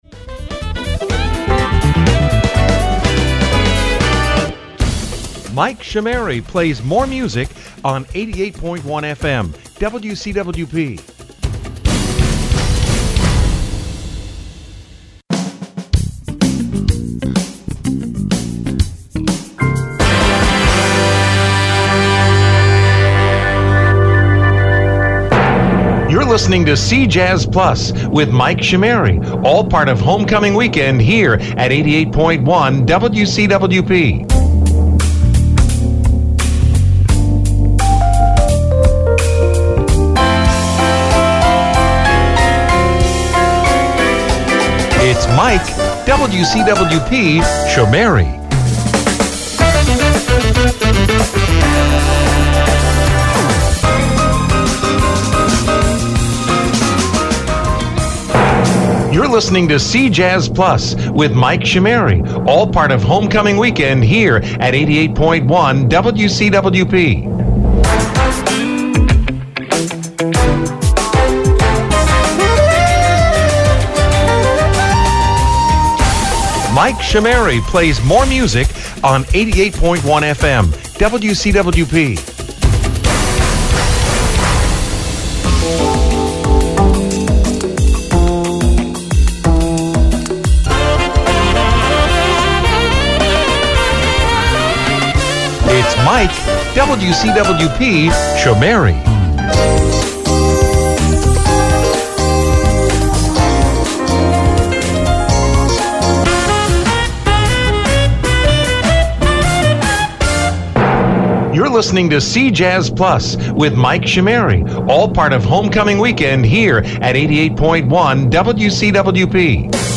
The first transition was from the console while the rest were from the Internet stream.